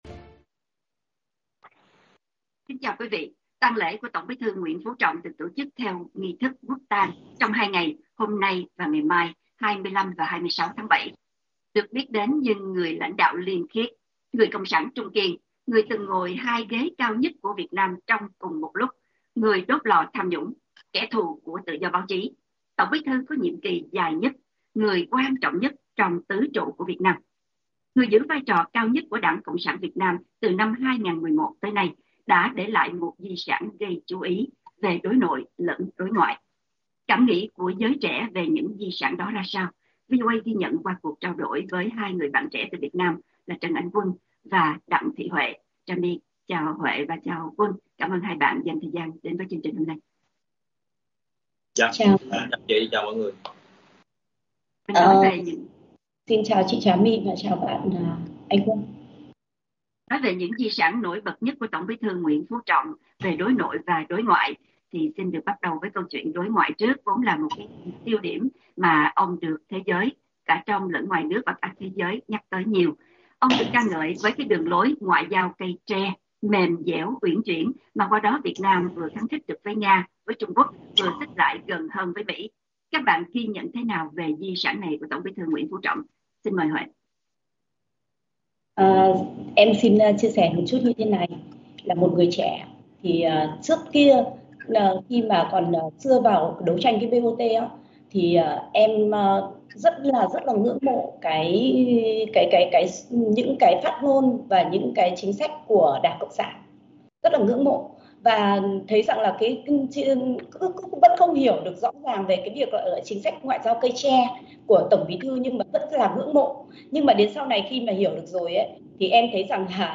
Cảm nhận của giới trẻ về những di sản đó ra sao? VOA ghi nhận qua cuộc trao đổi với 3 bạn trẻ từ Việt Nam